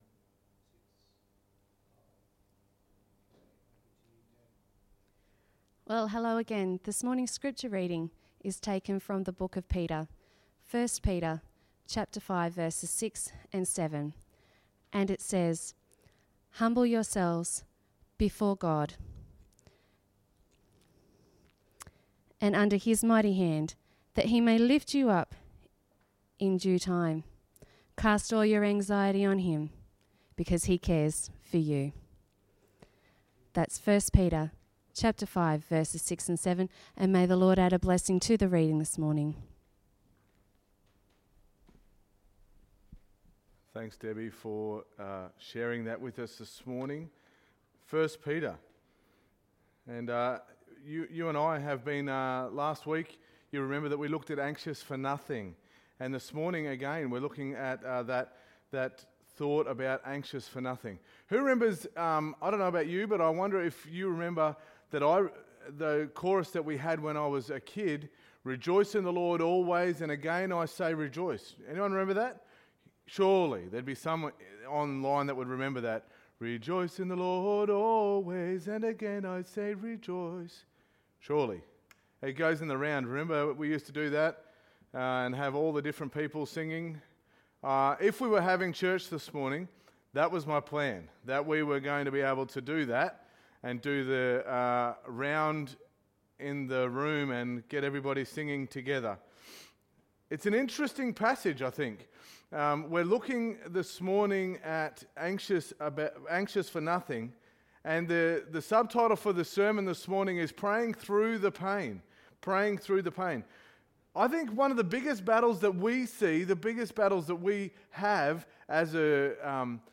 Sermon 22.03.2020